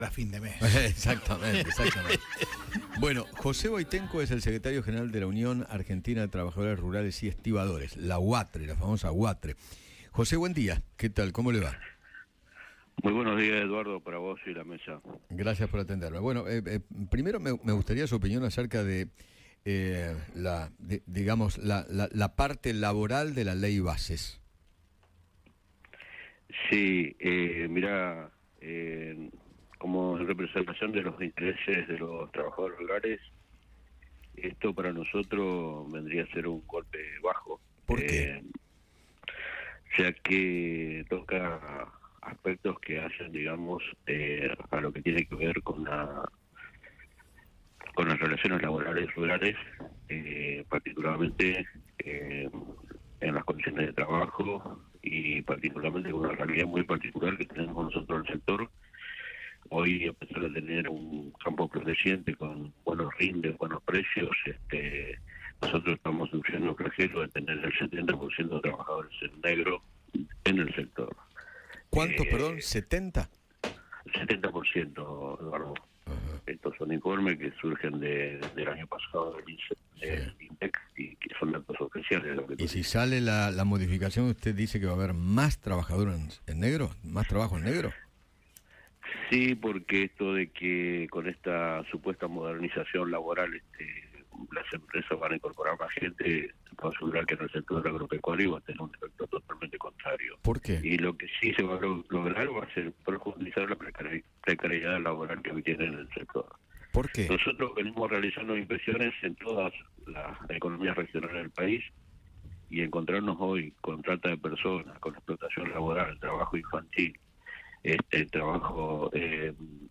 conversó con Eduardo Feinmann sobre la precarización laboral de los trabajadores rurales y el impacto de la “modernización laboral” que plantea la Ley Bases.